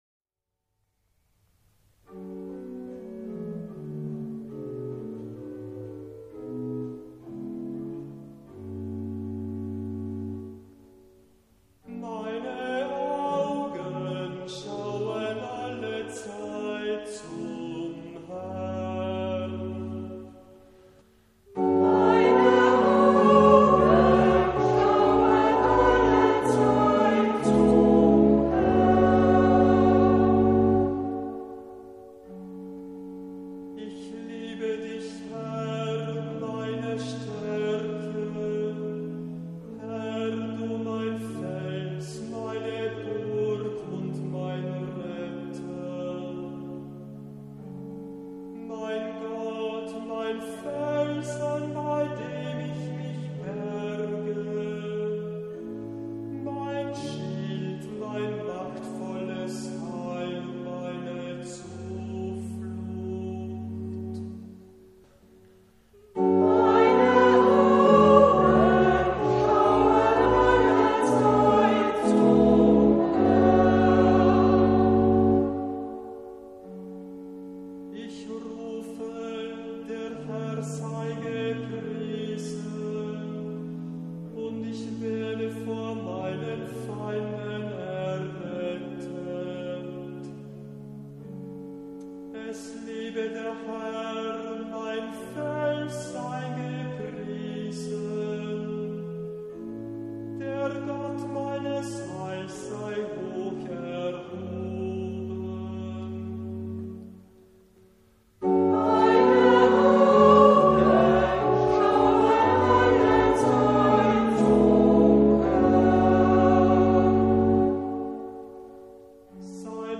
Antwortpsalmen - Oktober 2014
Freiburger Kantorenbuch Nr. 115 Orgel
Kantor